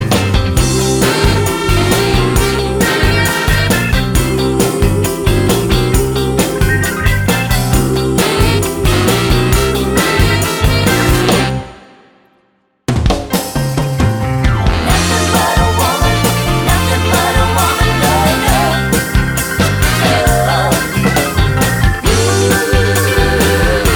Live Pop (1960s) 3:07 Buy £1.50